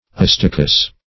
Astacus \As"ta*cus\, n. [L. astacus a crab, Gr.